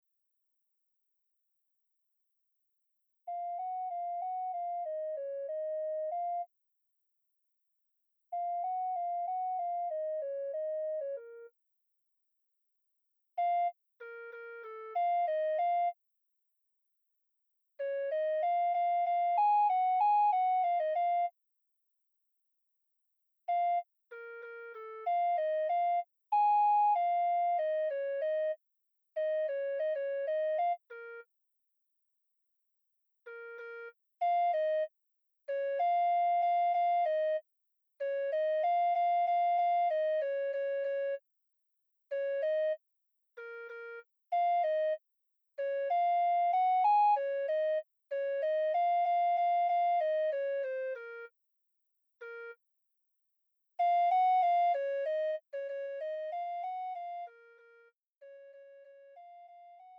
음정 원키
장르 구분 Pro MR